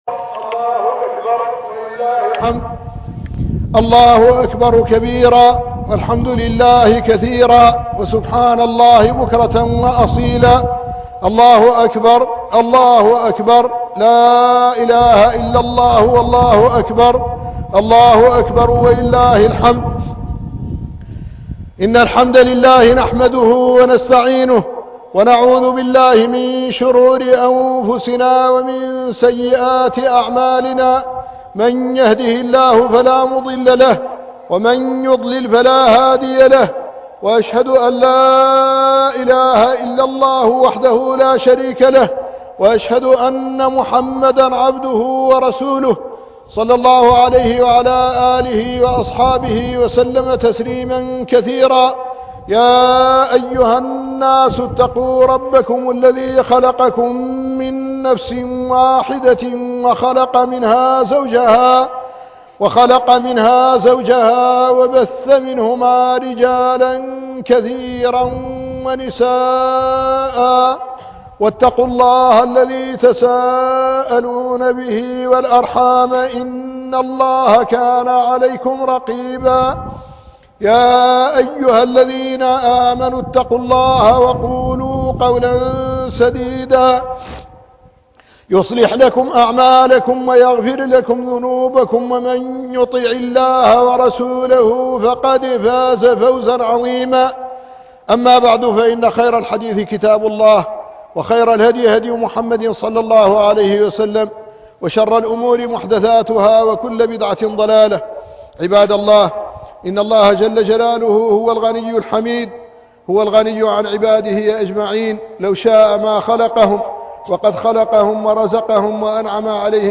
خطبة عيد الأضحى 1440